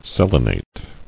[sel·e·nate]